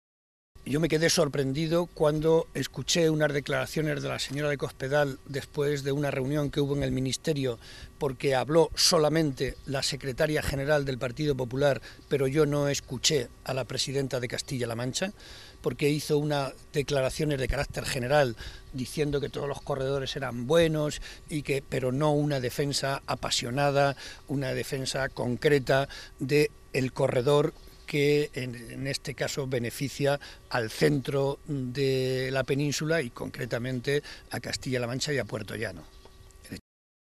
José María Barreda, presidente del Grupo Parlamentario Socialista
Cortes de audio de la rueda de prensa